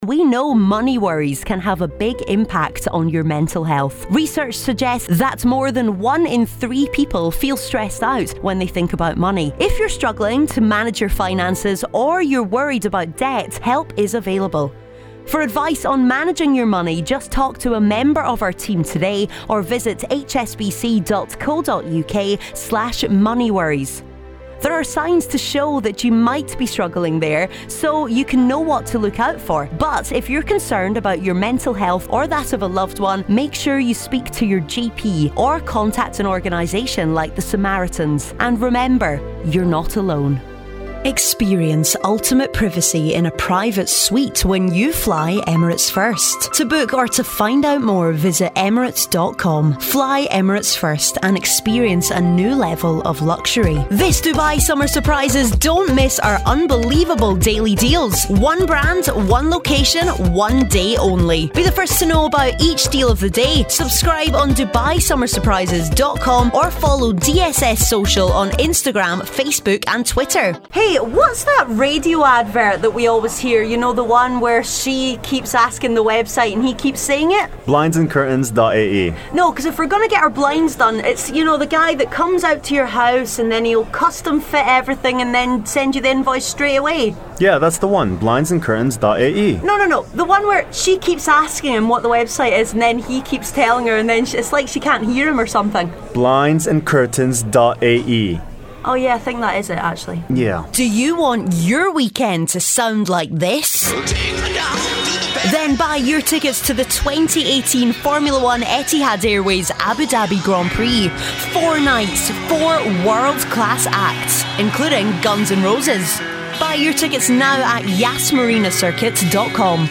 Foreign & British Female Voice Over Artists & Actors
Adult (30-50)